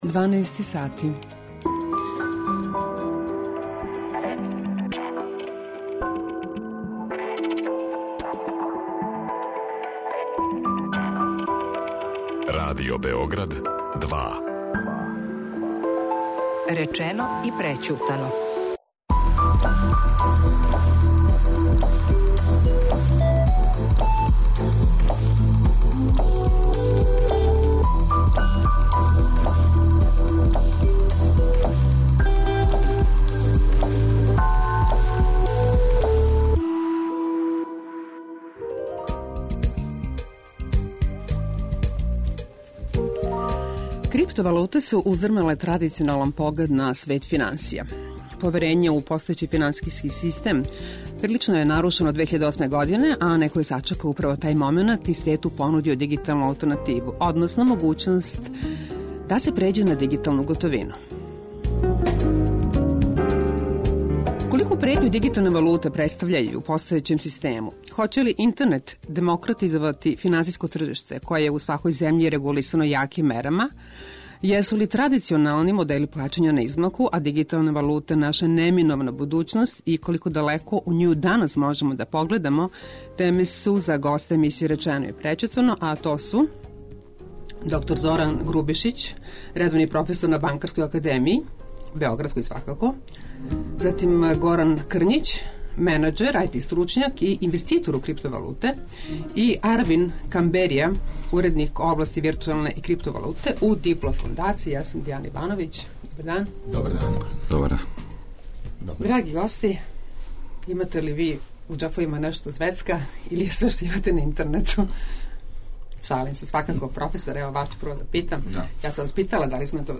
О овим темама говоре гости емисије Речено и прећутано: